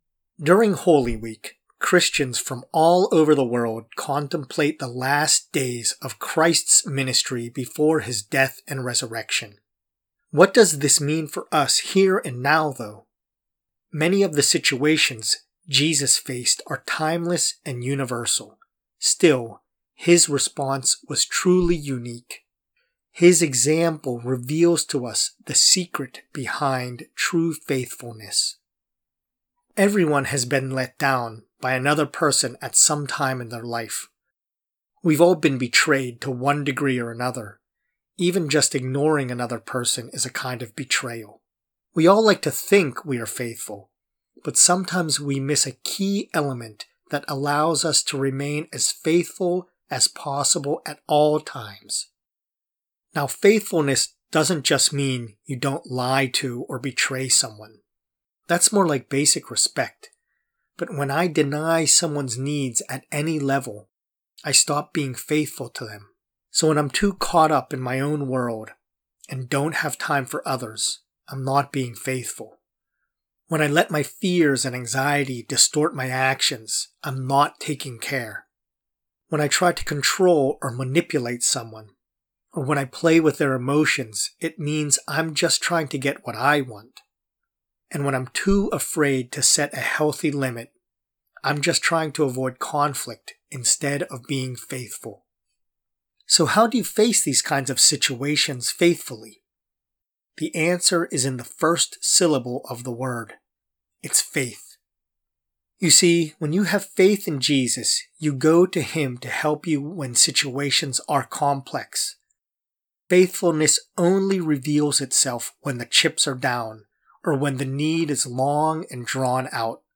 A message and prayer